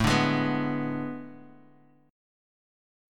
AM7sus2sus4 chord